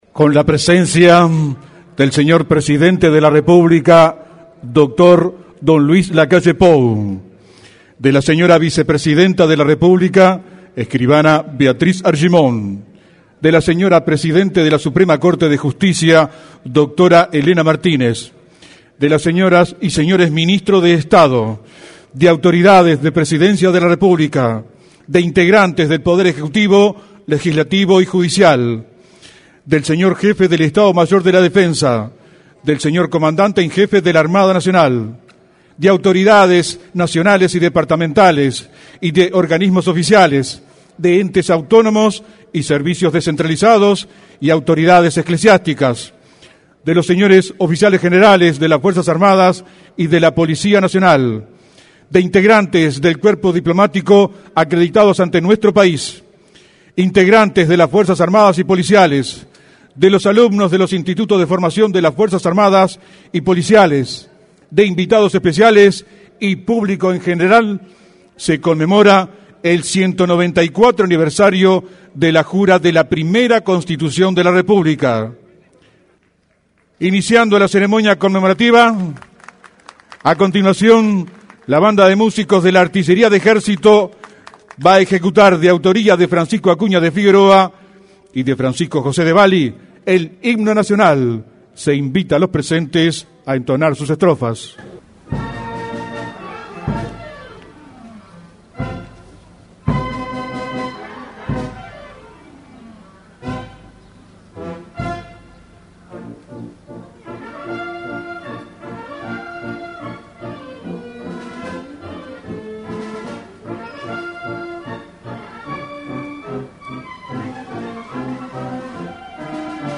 En la oportunidad, se expresó el secretario de la Presidencia de la República, Rodrigo Ferrés. Una vez culminado el acto, se realizó un desfile cívico-militar.